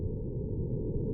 loop-slow-down.ogg